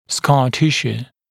[skɑː ‘tɪʃuː] [-sjuː][ска: ‘тишу:] [-сйу:]рубцовая ткань